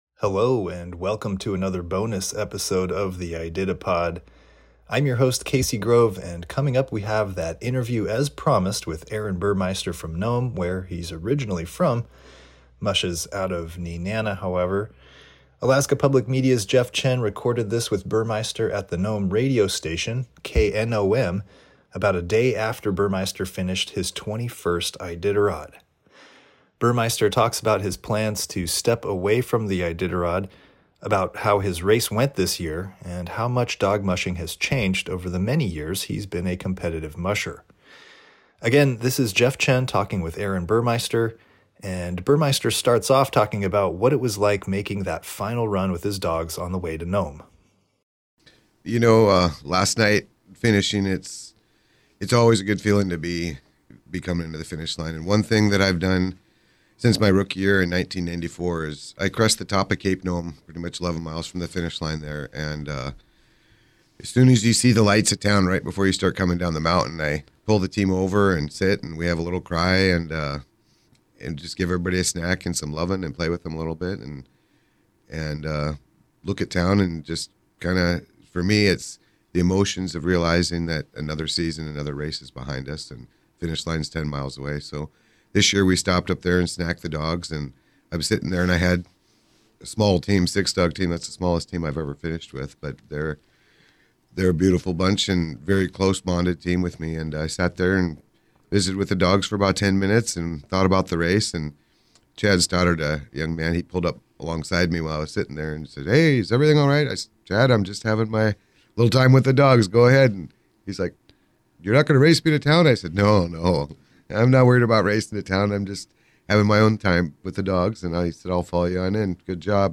interview in Nome